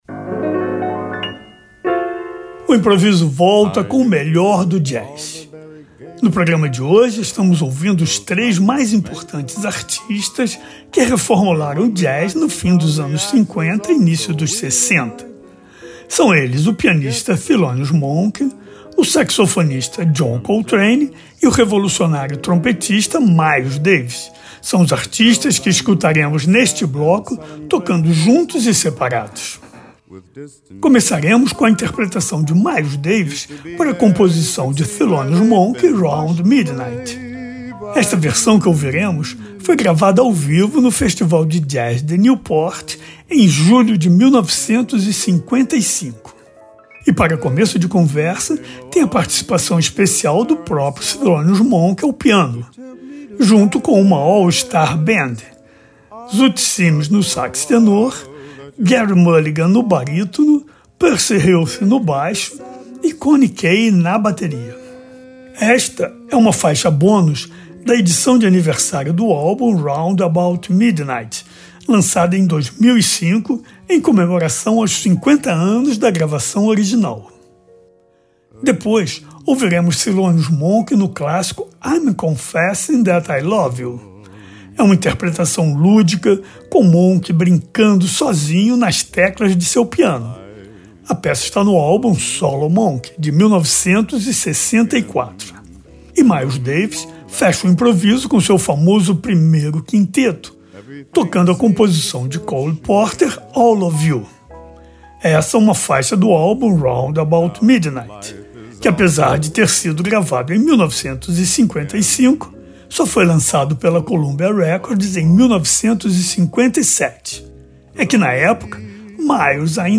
pianista
saxofonista
trompetista